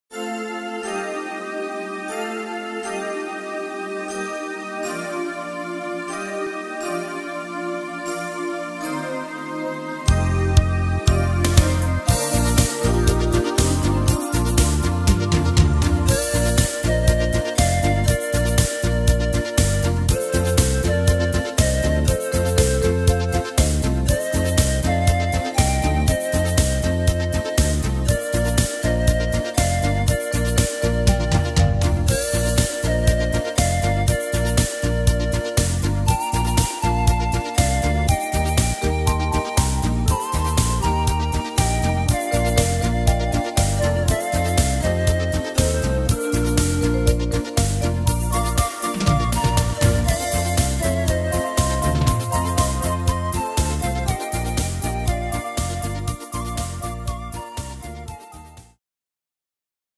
Tempo: 120 / Tonart: Bb -Dur